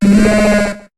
Cri d'Écrémeuh dans Pokémon HOME.